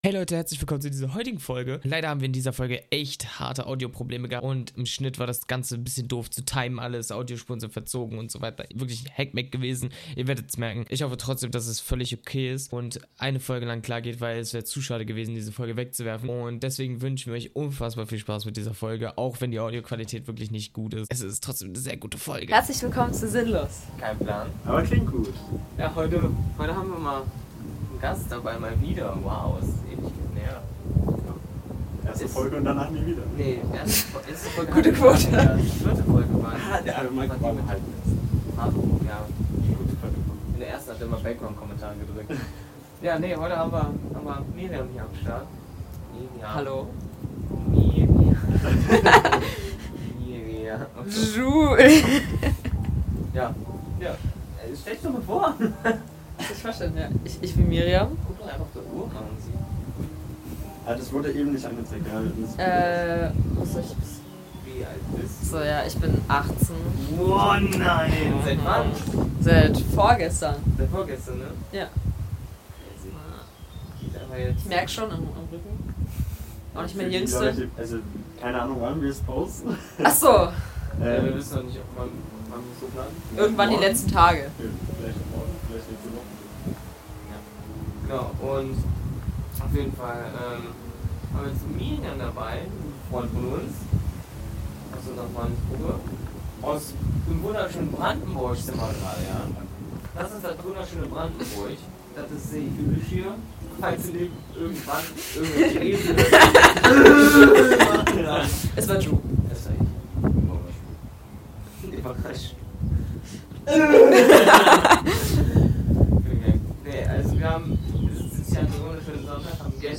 Heute sitzen wir in ihrem Garten und reden über diese Geburtstagsfeier und darüber